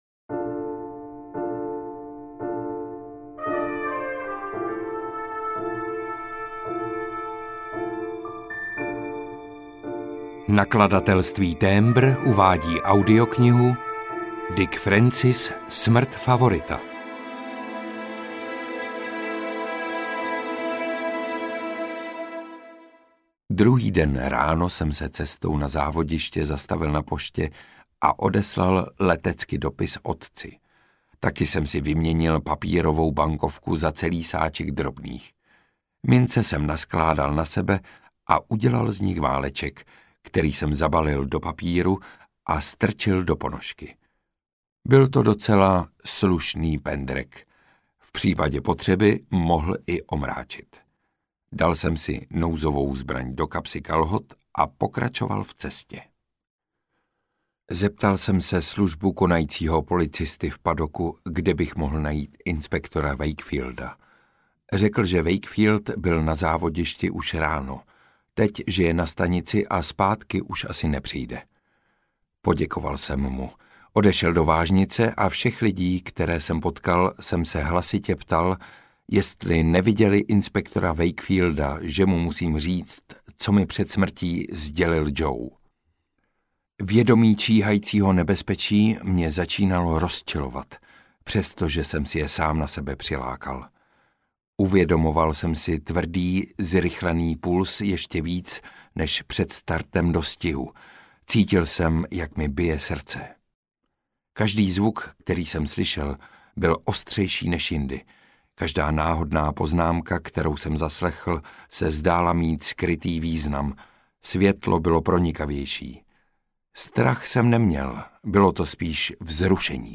Audiokniha Smrt favorita, kterou napsal Dick Francis. V prvním detektivním příběhu Dicka Francise z roku 1962 pátrá amatérský jezdec Alan York po vrazích svého blízkého přítele, nejlepšího dostihového jezdce sezony.
Ukázka z knihy
• InterpretVáclav Rašilov